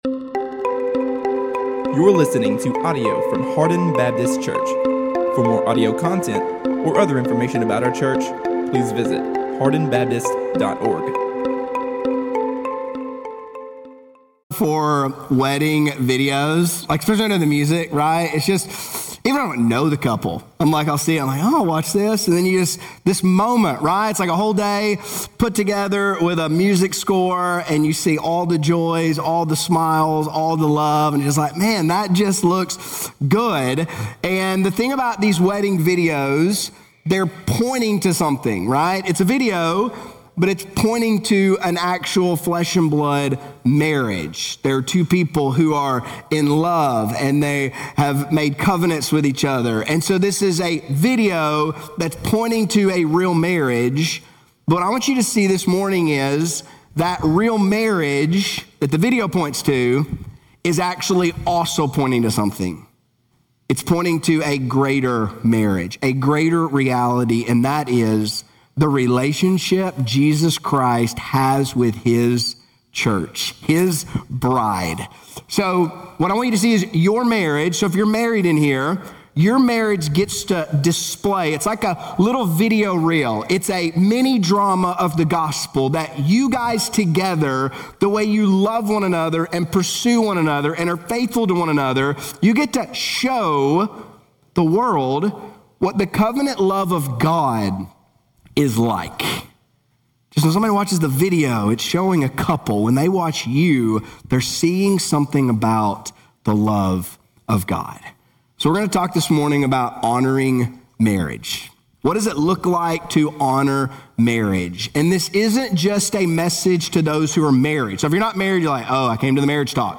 A message from the series "Hebrews 2025."